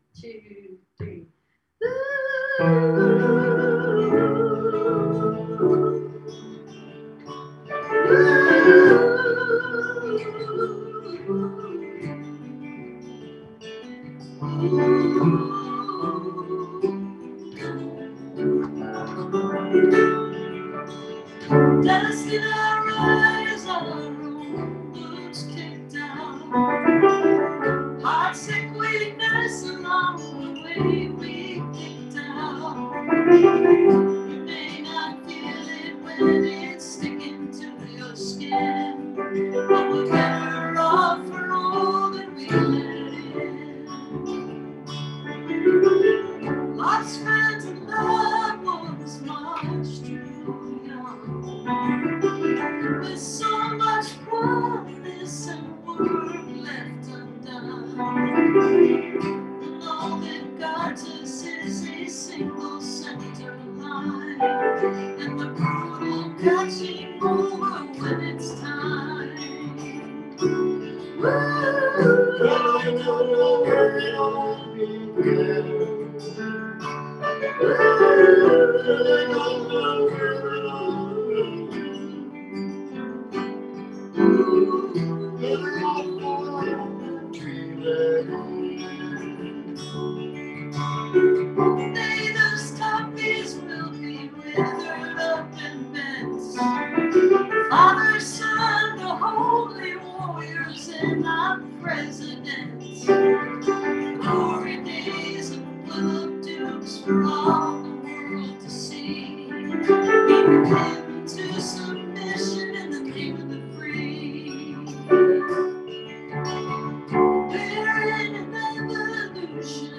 (captured from the zoom livestream)